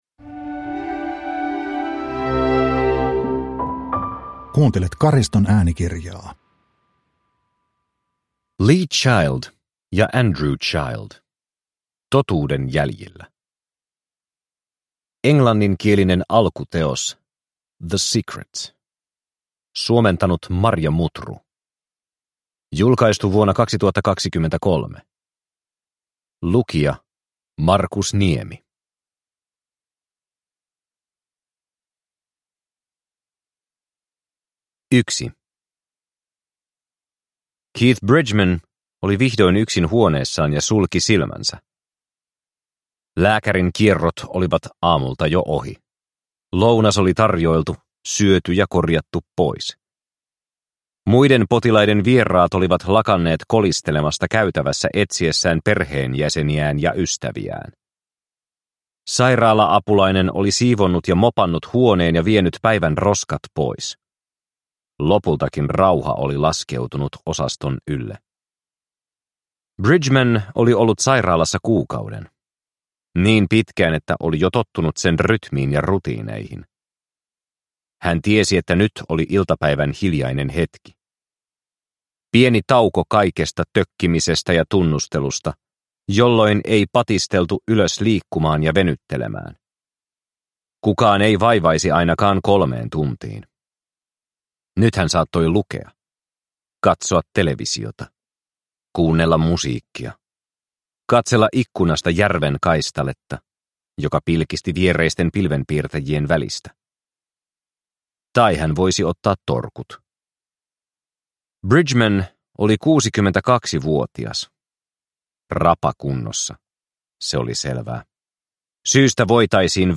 Totuuden jäljillä – Ljudbok – Laddas ner